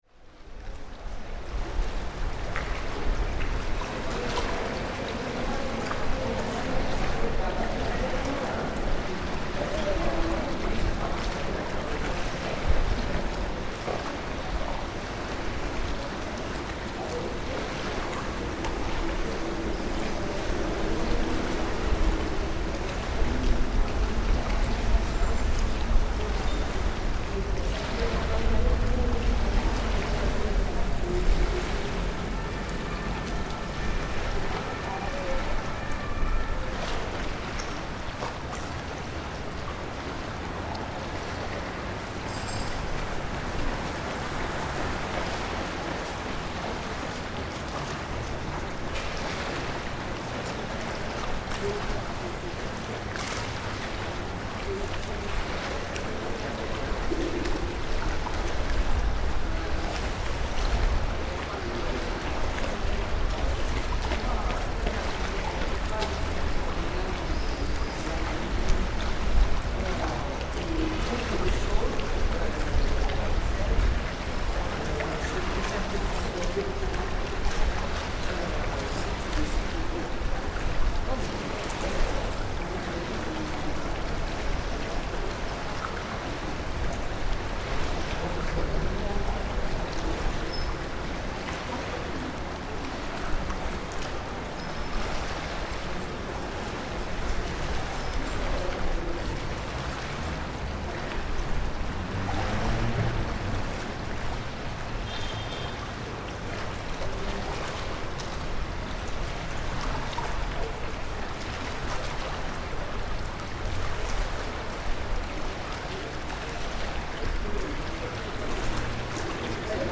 Joggers, cyclist and the Seine at Pont Neuf
Underneath Pont Neuf in Paris, we capture one on side the flowing of the River Seine, and on the other the classic Parisian sounds of walkers, joggers and cyclists in large numbers taking their weekend exercise and enjoying the views.